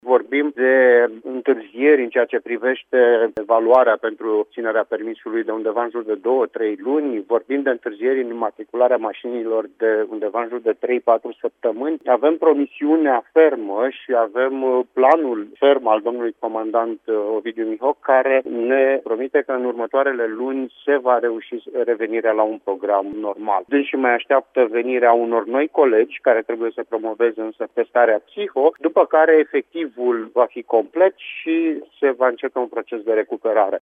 Subprefectul de Timiș, Sorin Ionescu, a declarat, la Radio Timișoara, că activitatea serviciului ar putea reveni la normal în următoarele luni, după ce personalul va fi completat.